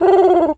pgs/Assets/Audio/Animal_Impersonations/pigeon_call_calm_08.wav at master
pigeon_call_calm_08.wav